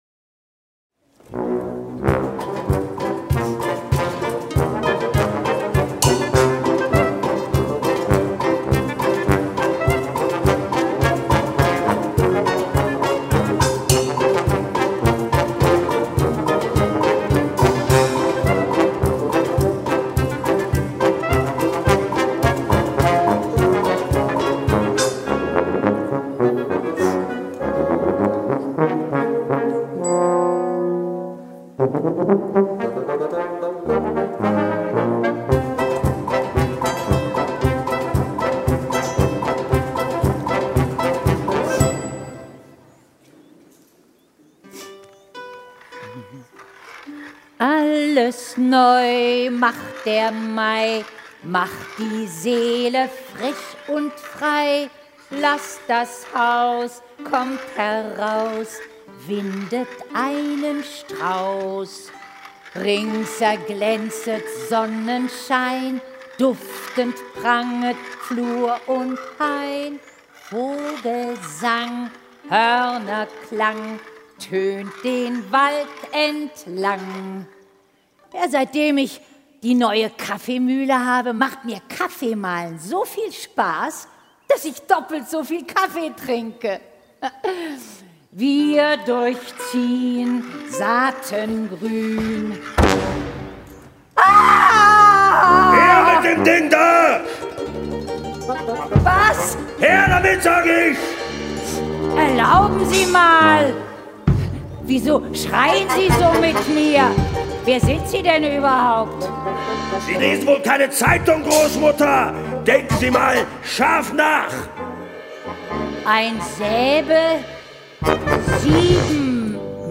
Live-Hörspiel (1 CD)
Ein kunterbunt inszeniertes Live-Hörspiel von den ARD-Kinderhörspieltagen – mit beschwingt-heiterer Musik und Tatort-Kommissar Wolfram Koch als Räuber Hotzenplotz. Der Räuber Hotzenplotz hat Großmutters Kaffeemühle gestohlen.